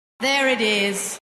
The speaker, Alice Tumler, has a basically Southern-British type of accent; according to her Wikipedia page, she studied for some time in London.
And to be fair, Ms Tumler occasionally did use a linking r during the show, for example in ‘there‿it is’: